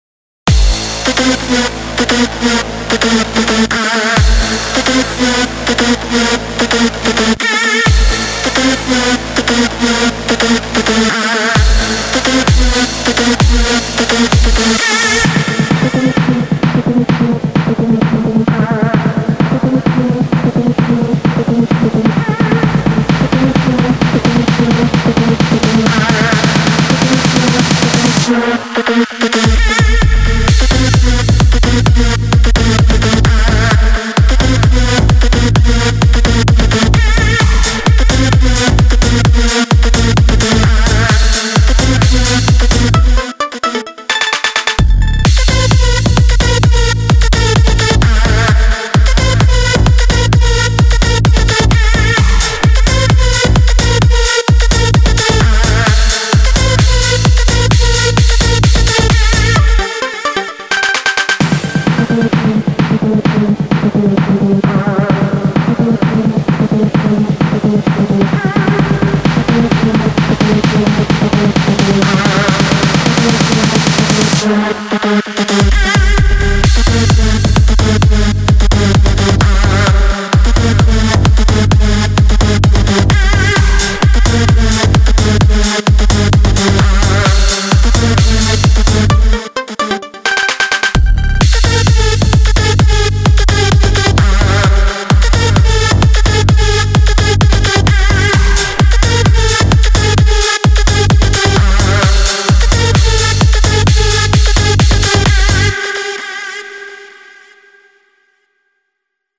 הטראק נגמר חד מדי